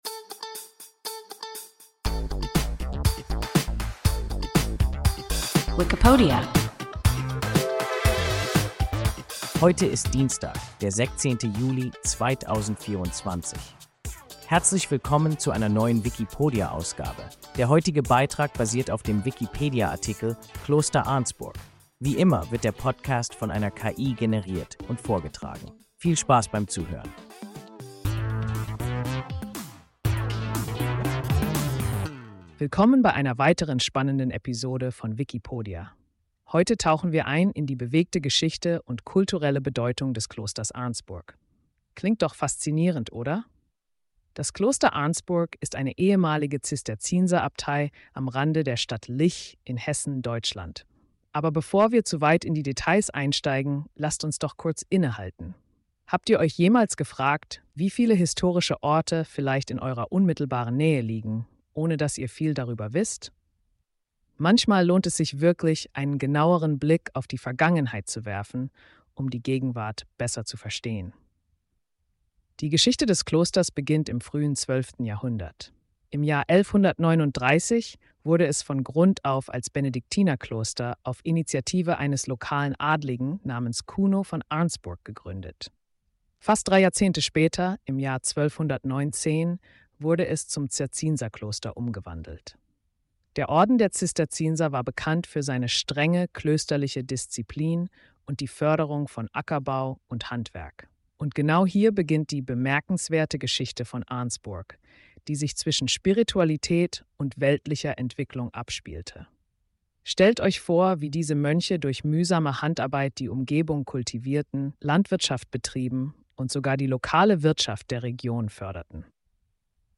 Kloster Arnsburg – WIKIPODIA – ein KI Podcast